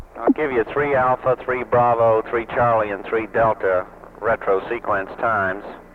capcom_orbit_misc_13.wav